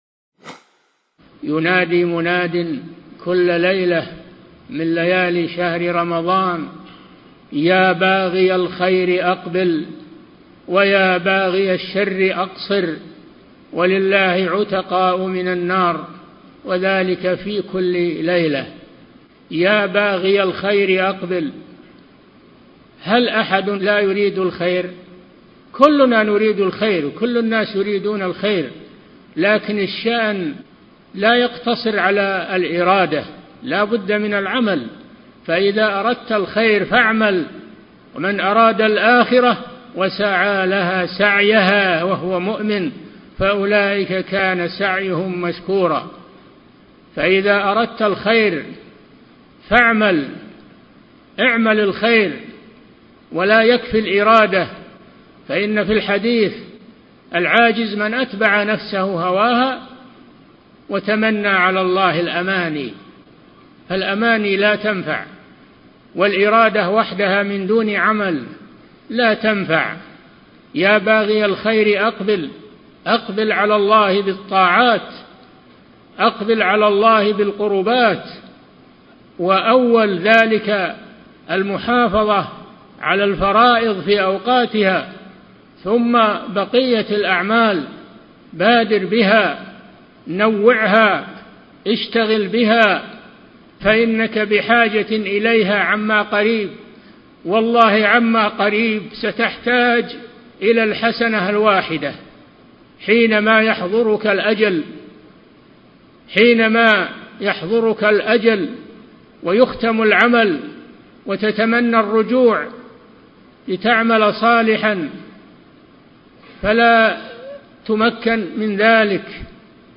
من مواعظ أهل العلم